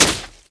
/hl2/sound/npc/antlion_guard/near/
foot_light2.ogg